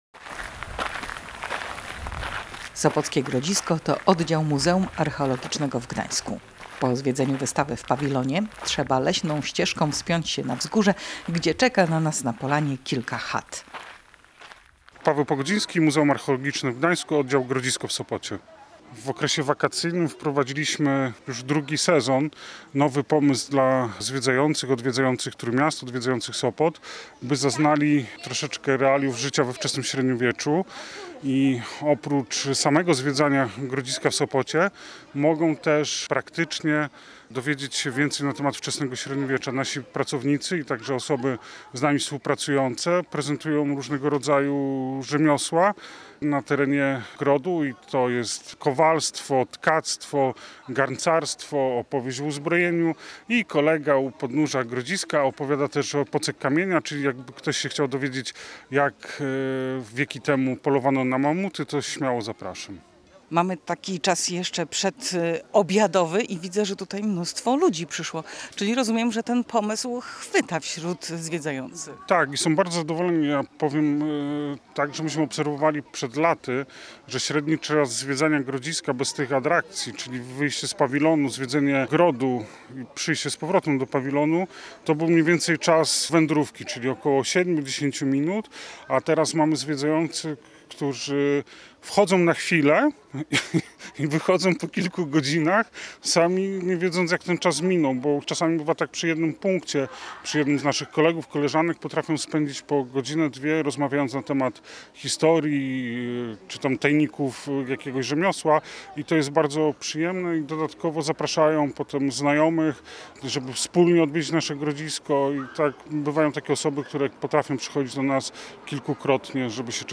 Lato na Grodzisku w Sopocie jest wyjątkowe.
Pracownicy w strojach z epoki opowiadają o słowiańskim grodzie, czyli osadzie obronnej która istniała tu dużo wcześniej zanim powstało miasto.